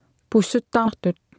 Martha tassaavoq kalaallisut qarasaasiakkut atuffassissut.